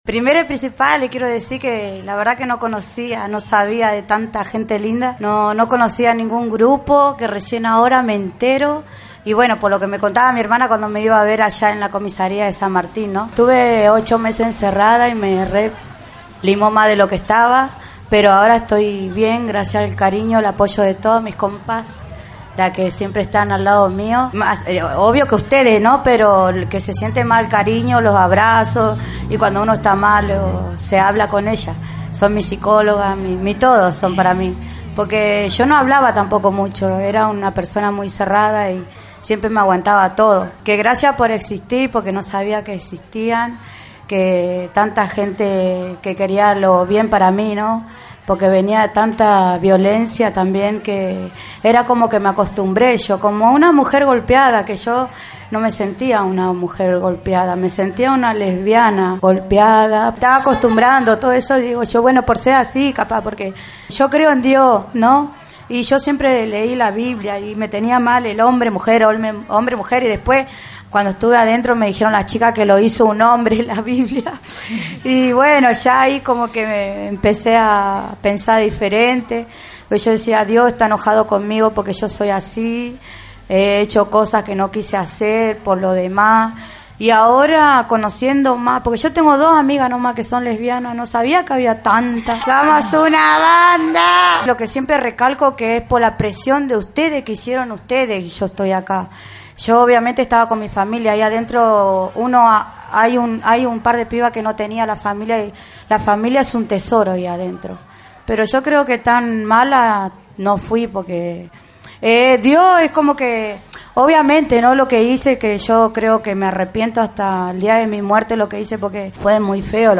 en la radio abierta del Encuentro Regional de Mujeres, Lesbianas, Travestis y Trans en Merlo.